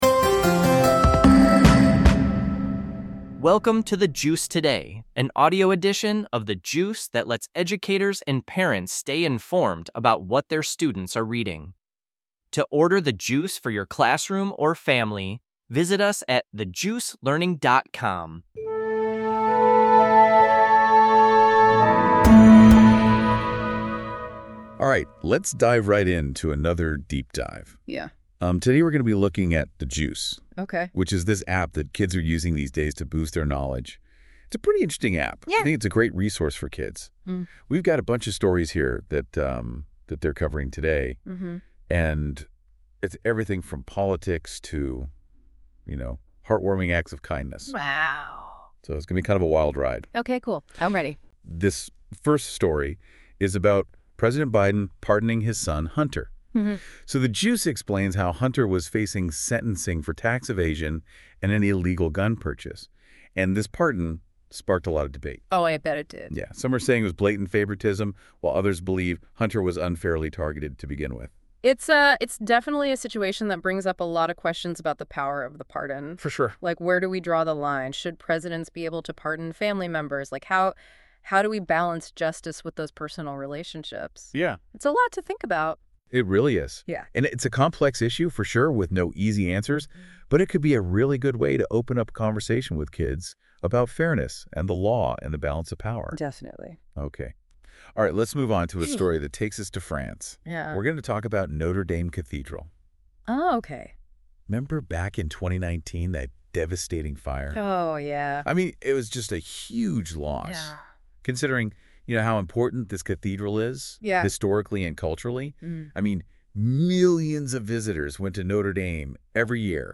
Words of the Year.Visit Us OnlineThe Juice Learning (for Educators) The Juice Today (for Parents)Production NotesThis podcast is produced by AI